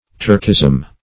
Search Result for " turkism" : The Collaborative International Dictionary of English v.0.48: Turkism \Turk"ism\, n. A Turkish idiom or expression; also, in general, a Turkish mode or custom.